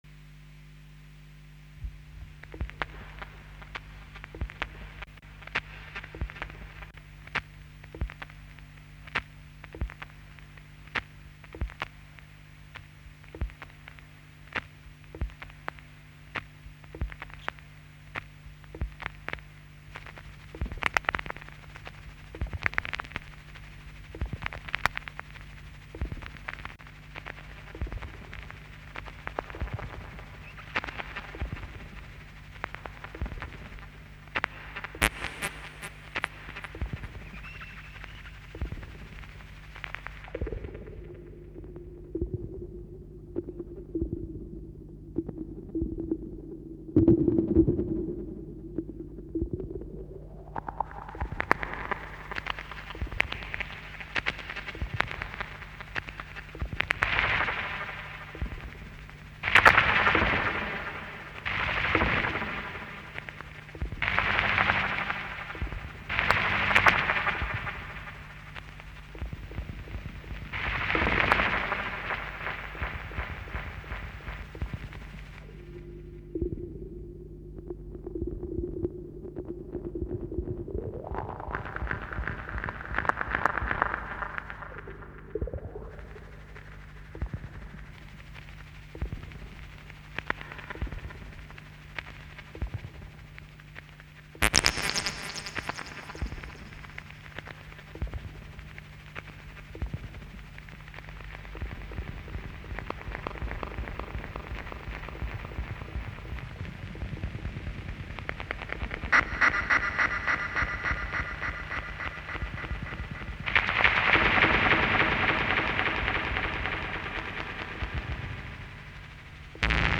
Japanese and German electronic musicians with short bios